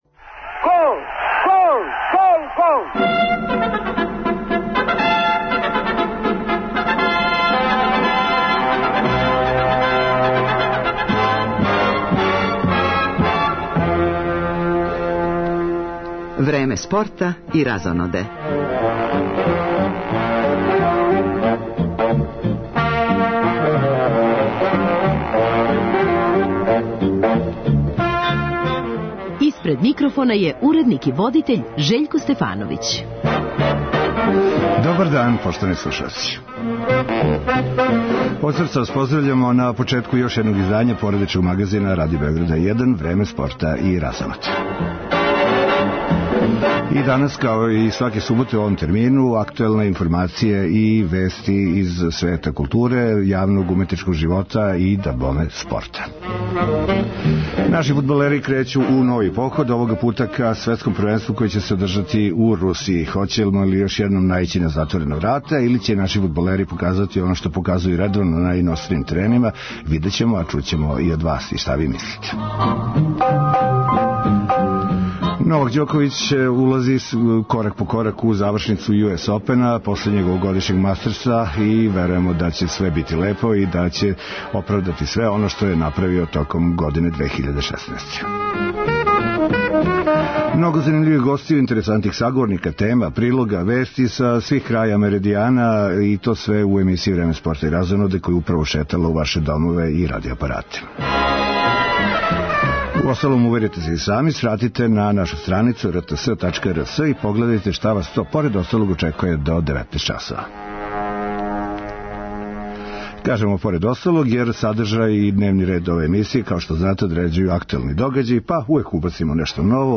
Ове суботе најављујемо почетак квалификација фудбалске репрезентације Србије за Светско првенство у Москви, чућемо бројне изабранике новог селектора Славољуба Муслина, али и прогнозе и очекивања наших слушалаца, уочи прве утакмице против Републике Ирске.